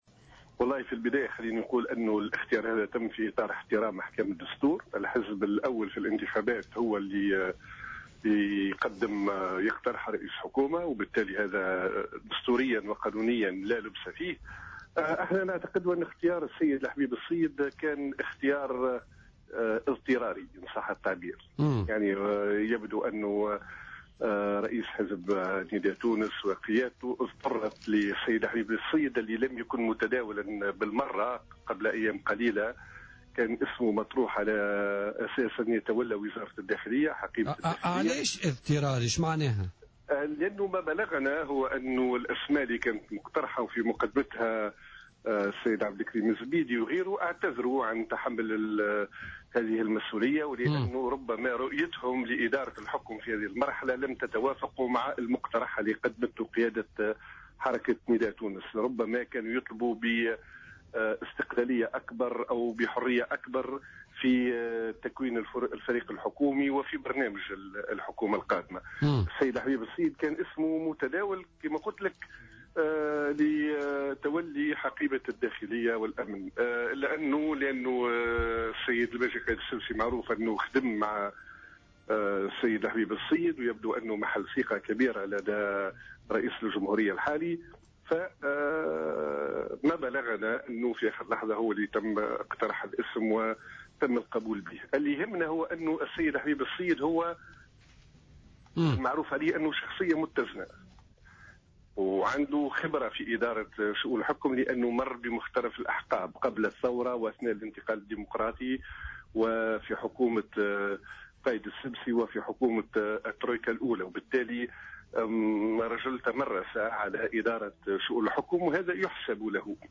Issam Chebbi, leader au parti Républicain, est intervenu sur les ondes de Jawhara FM dans le cadre de l’émission Politica du mardi 6 janvier 2015 pour commenter la désignation d’Habib Essid à la tête du nouveau gouvernement.